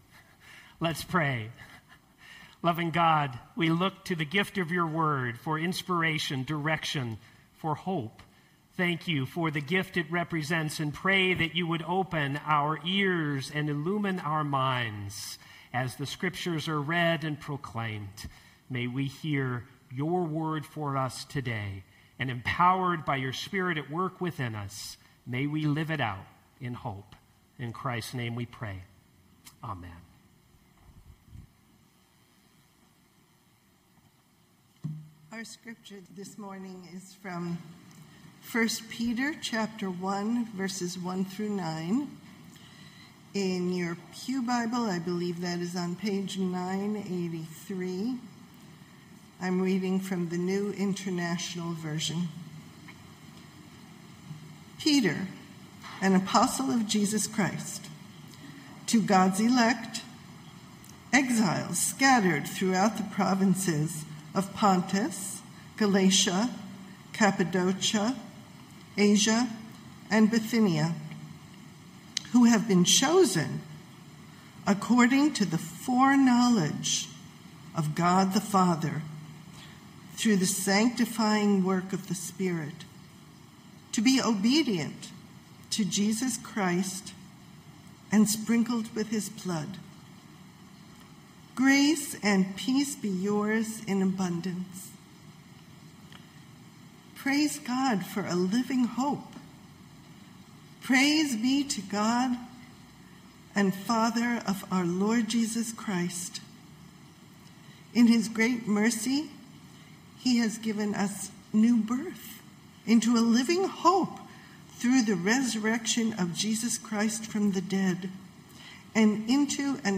Knox Pasadena Sermons New Every Morning Jul 27 2025 | 00:24:52 1x 00:00 / 00:24:52 Subscribe Share Spotify RSS Feed Share Link Embed